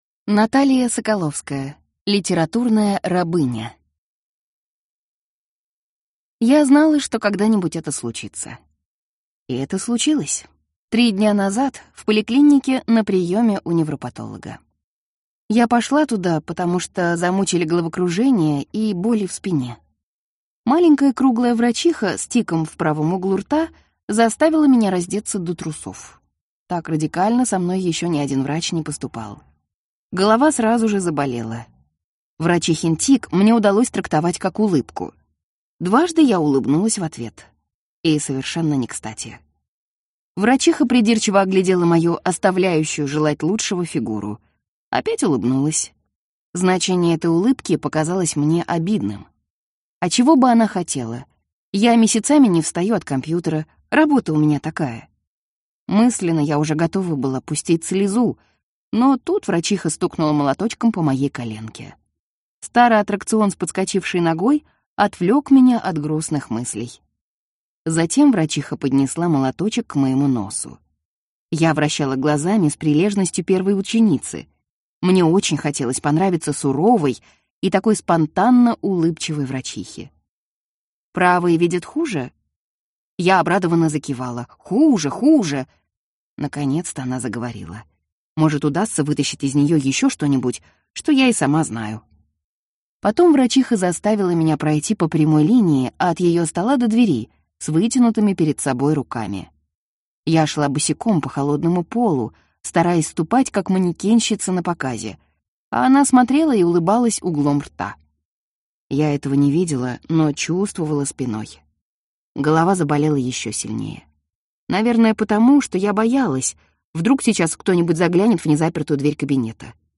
Аудиокнига Литературная рабыня | Библиотека аудиокниг